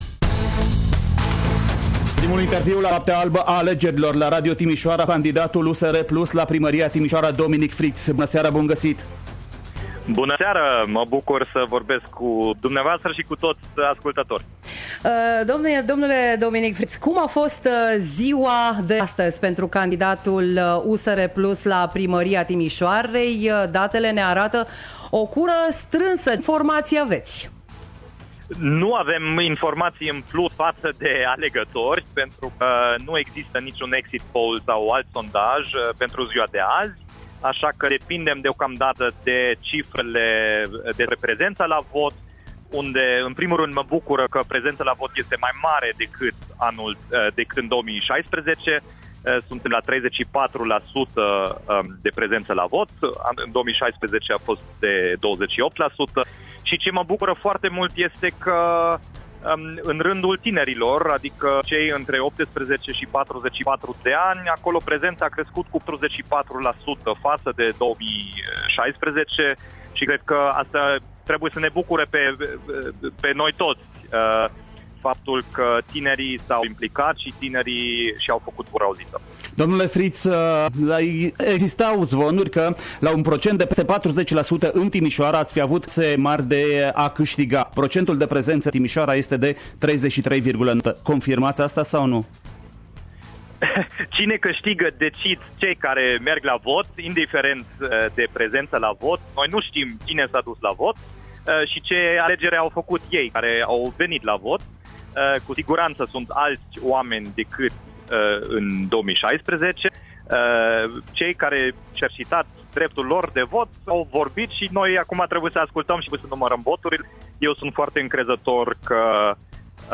Dominic Fritz – candidatul Alianței USR Plus , a fost în direct pe frecvențele noastre, cu primele reacții după alegeri.